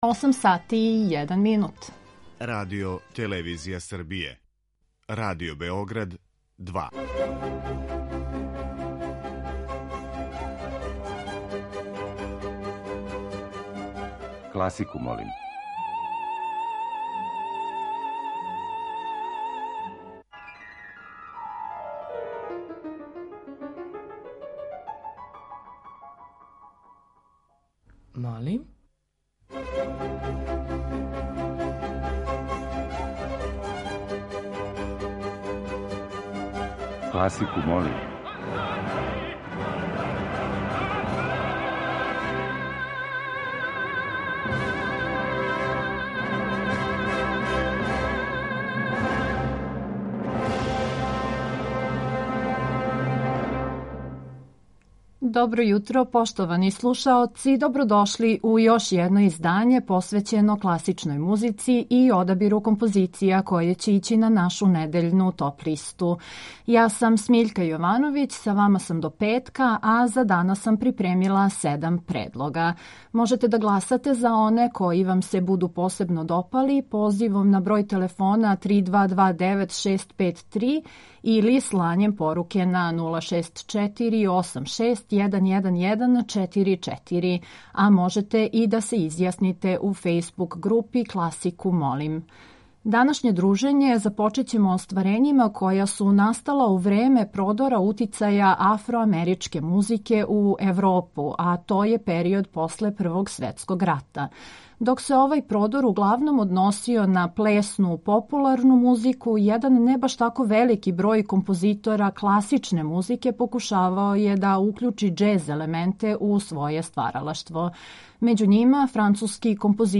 И ове седмице, од понедељка до четвртка емитоваћемо разноврсне предлоге композиција класичне музике.